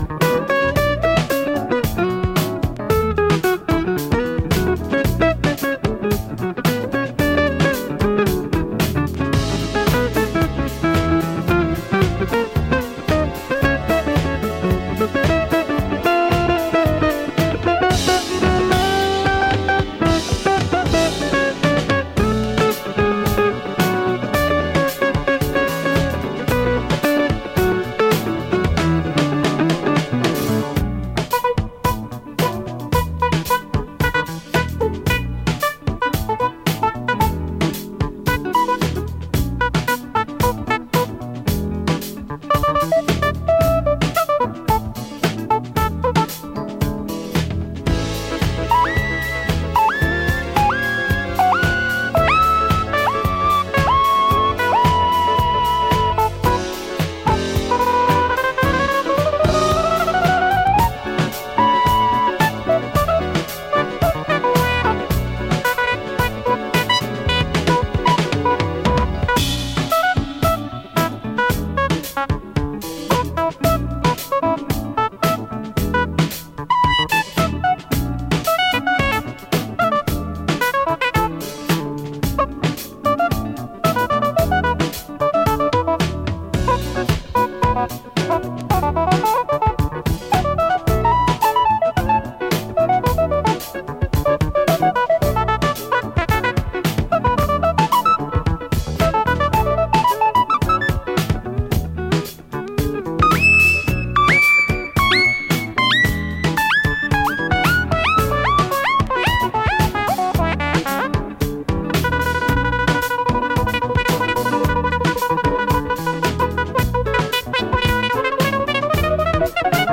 Hammond B3 organ
slick guitar-led floater
Funk / Soul